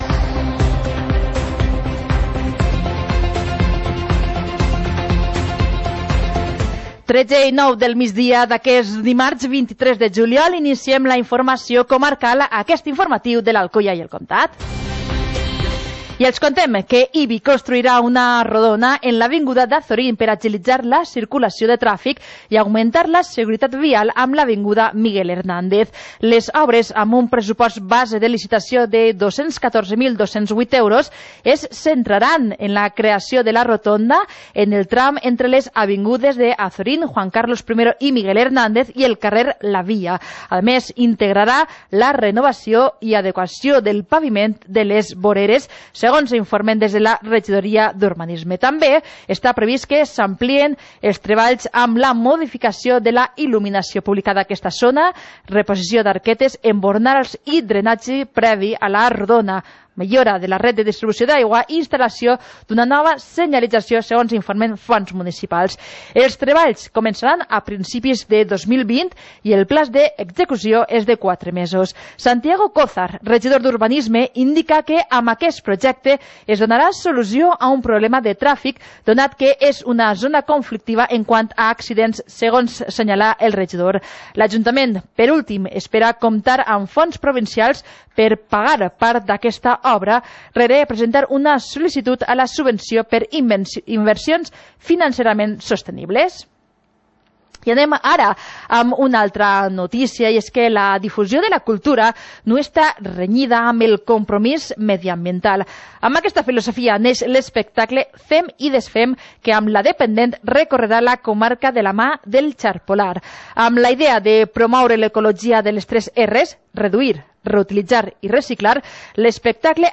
Informativo comarcal - martes, 23 de julio de 2019